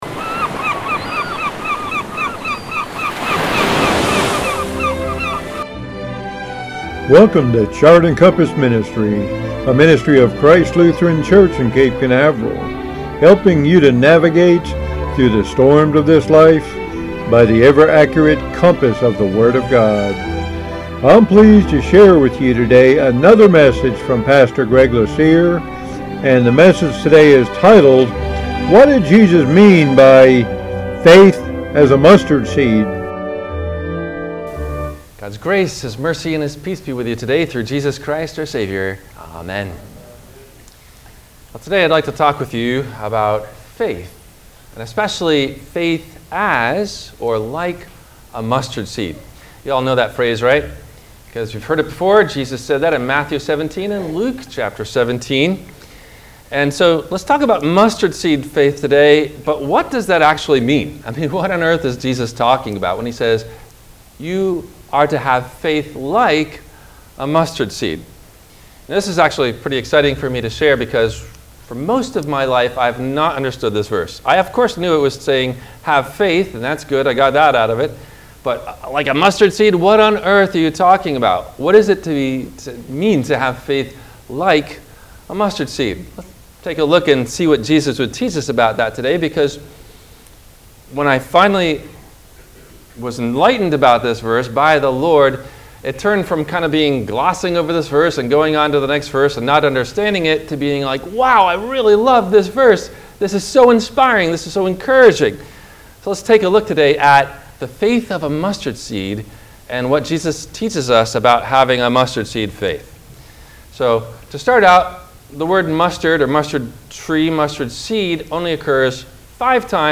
No questions asked before the Sermon message.